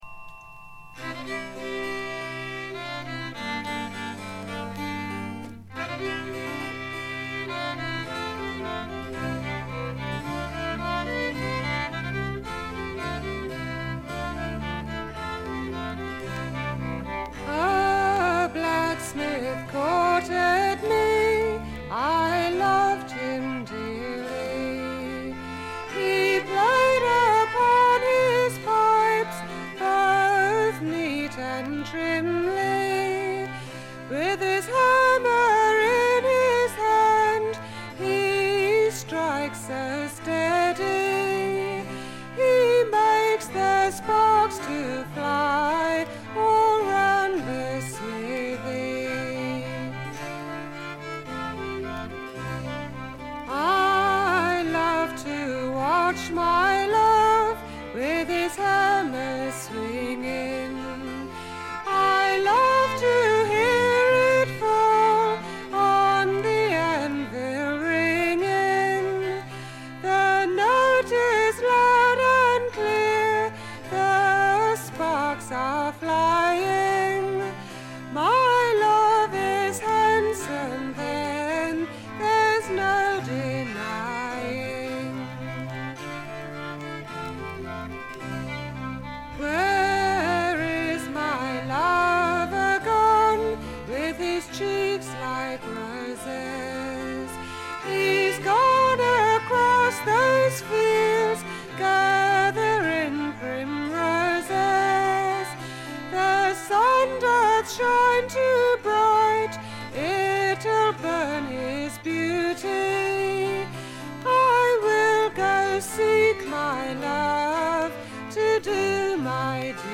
これ以外も軽い周回ノイズ、チリプチ、プツ音等多め大きめです。
試聴曲は現品からの取り込み音源です。